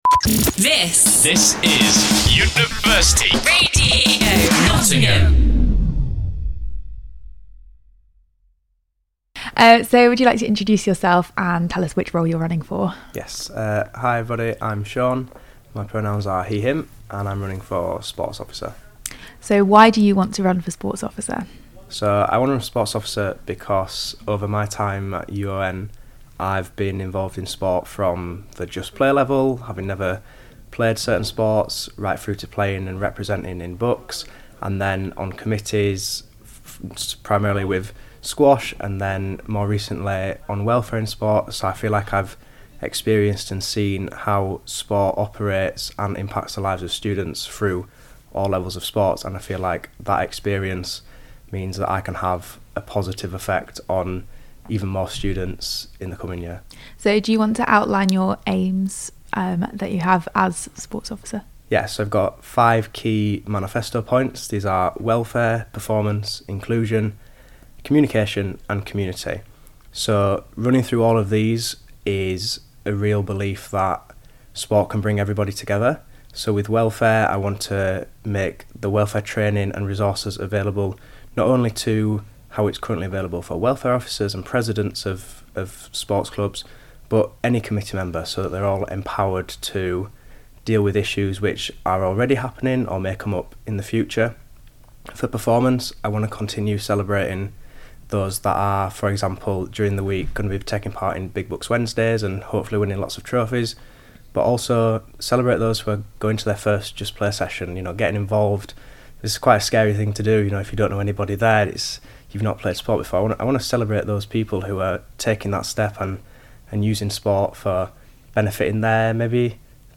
Our interview